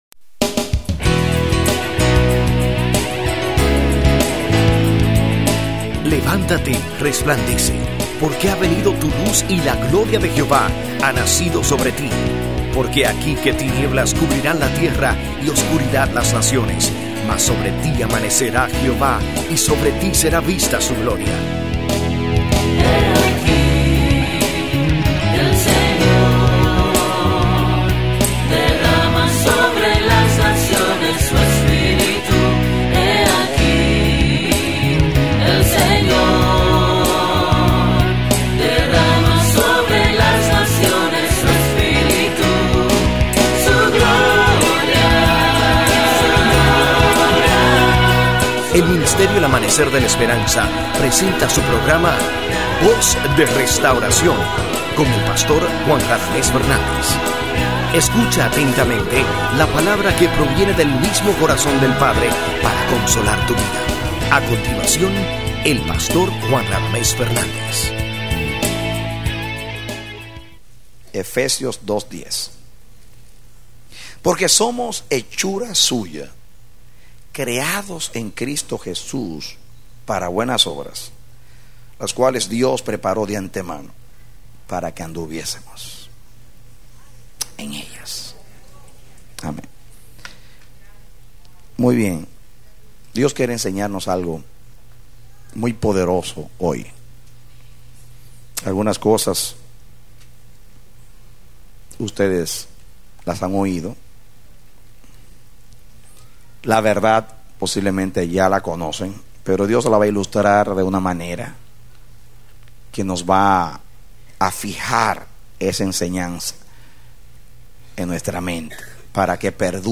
A mensajes from the series "Mensajes." Predicado Junio 30, 2002